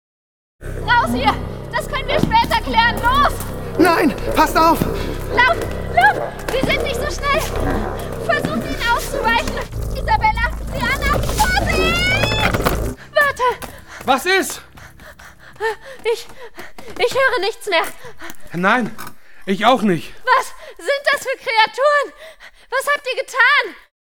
Joven, Accesible, Versátil, Cálida, Suave